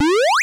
fsDOS_powerUp.wav